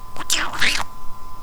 TASCAM タスカム DR-07MK2J [リニアPCMレコーダー]
いいや、それまでポンちゃん(飼ってるインコ)の声でも録音して遊ぼう。
で、録音してみました。『ポンちゃん、おはよう！』と聞こえますか？？ ｗｗ